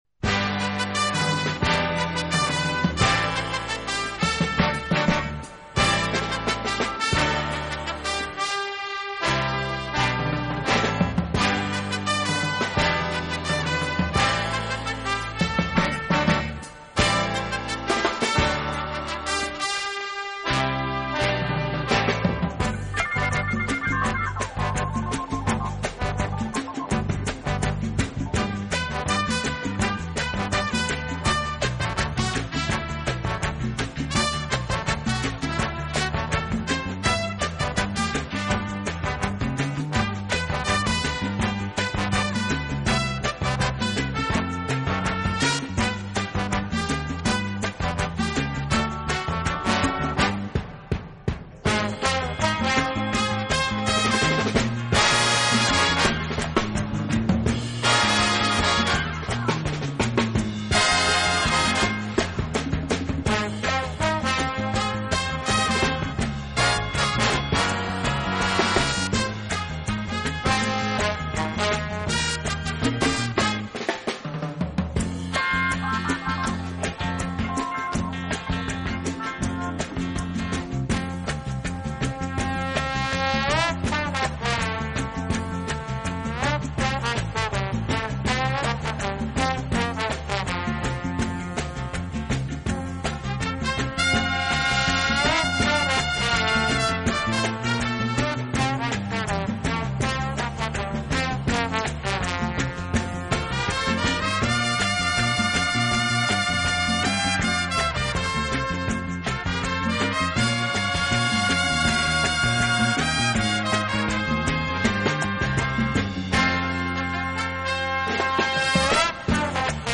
录制方式：AAD
此外，这个乐队还配置了一支训练有素，和声优美的伴唱合唱队。
这种宁静而优美的小曲即是